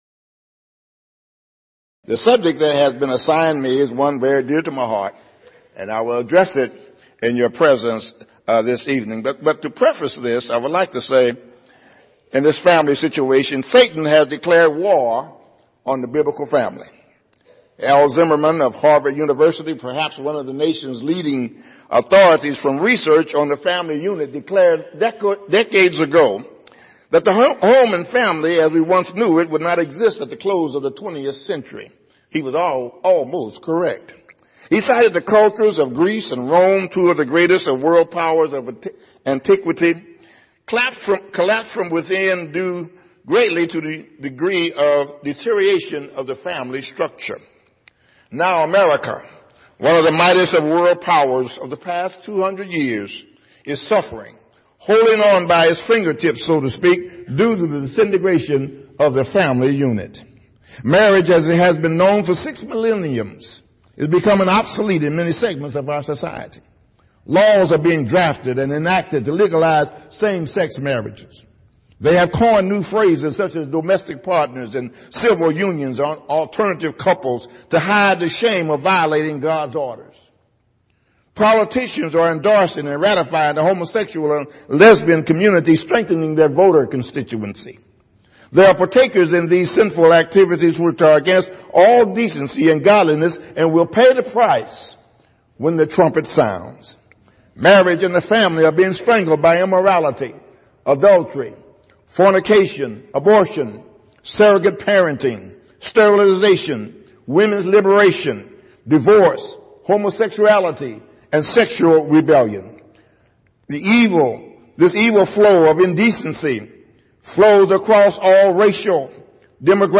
Event: 28th Annual Southwest Lectures Theme/Title: Honoring Christ: Calling For Godly Homes